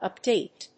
音節ùp・dáte 発音記号・読み方
/ˈʌpdeɪt(米国英語), ʌˈpdeɪt(英国英語)/